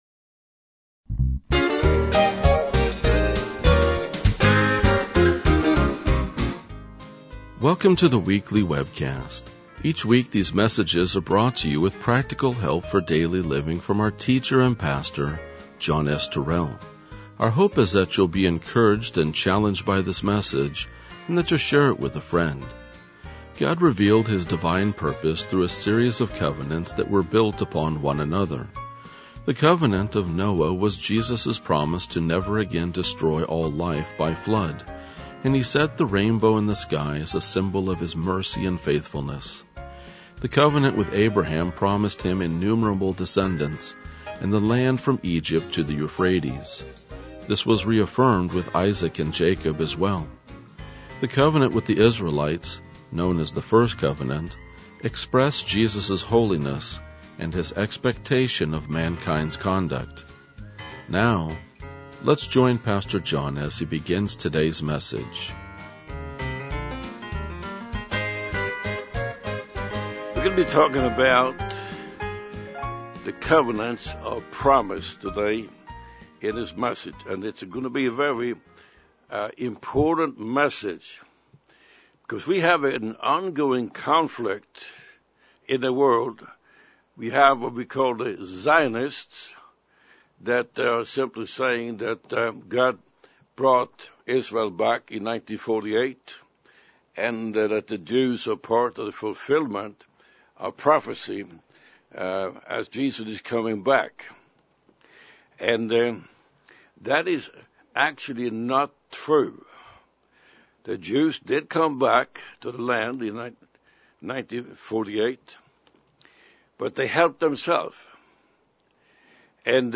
RLJ-2040-Sermon.mp3